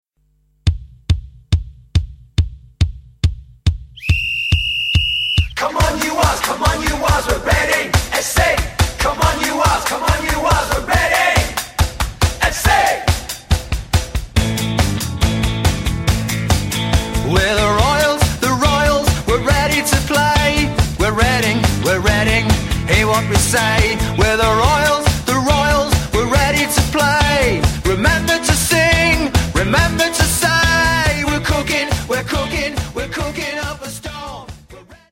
It is a pop song for Reading fans.